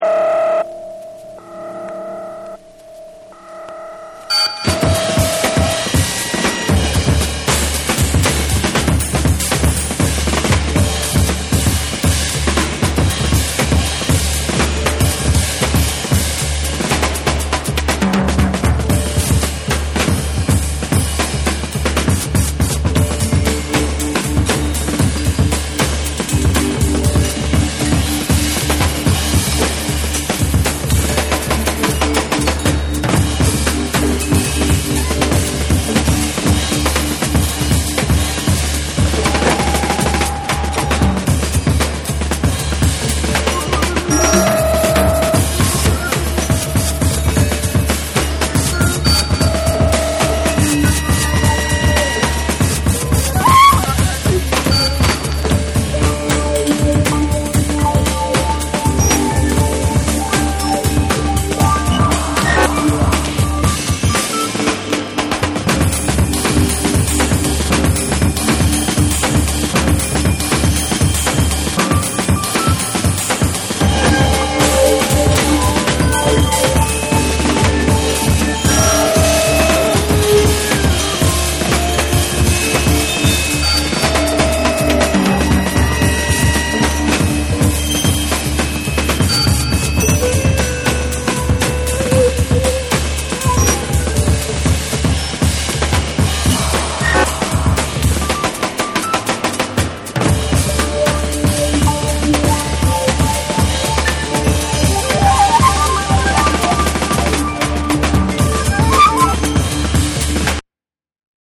ジャジーかつロックな雰囲気も打ち出す3は
BREAKBEATS / ORGANIC GROOVE